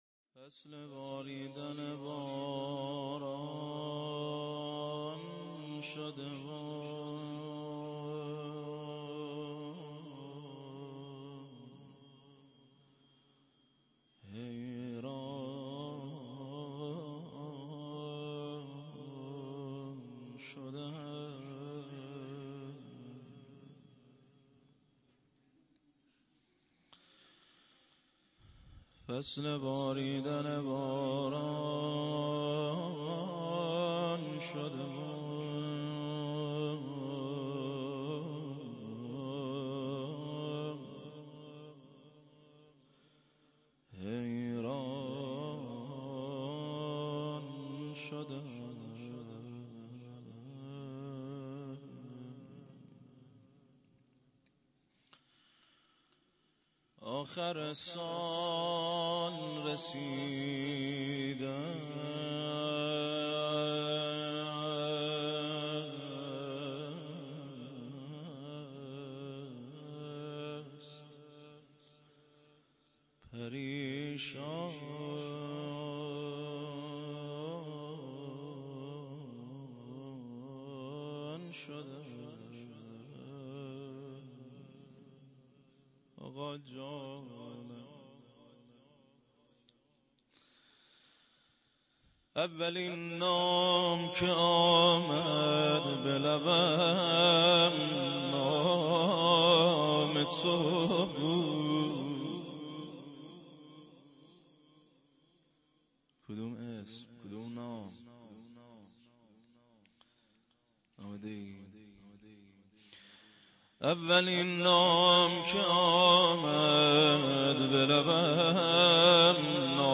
هیئت زواراباالمهدی(ع) بابلسر
0 0 روضه ام المومنین حضرت زهرا(س)